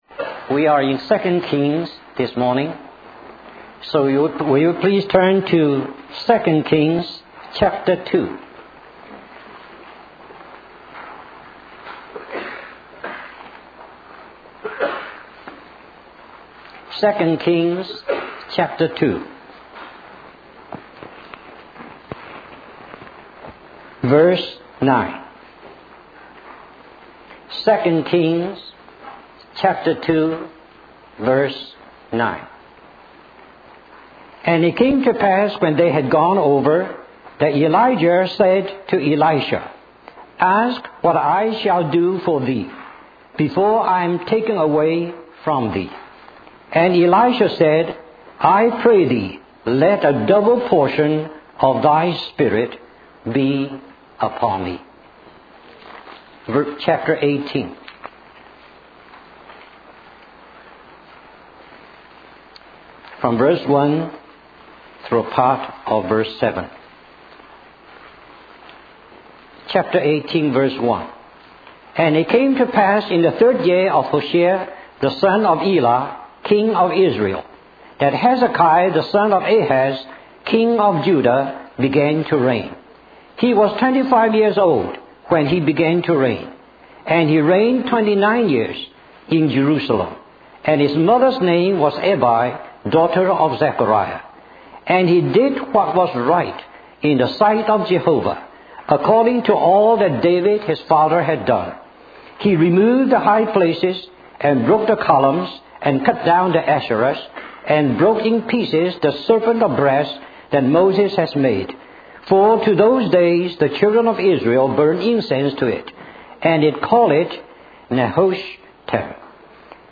In this sermon, the preacher discusses the importance of obeying God and doing His will.